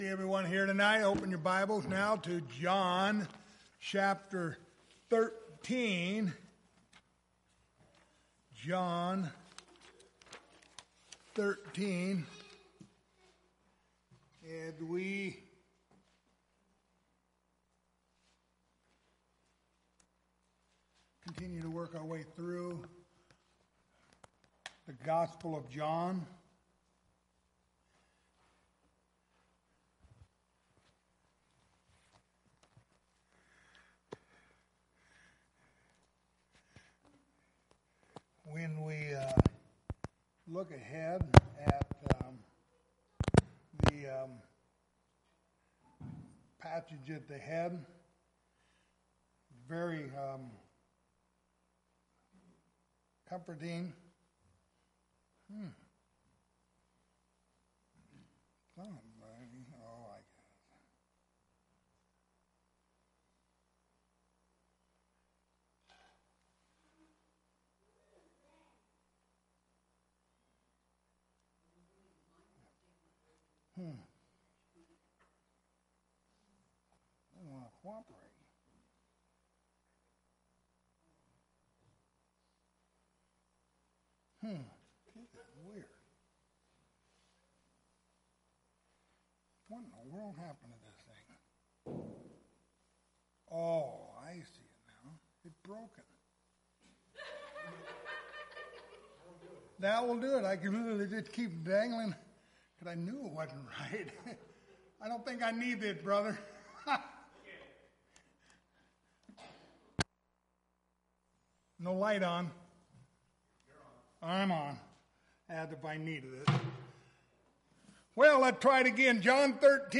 Passage: John 13:18-30 Service Type: Wednesday Evening Topics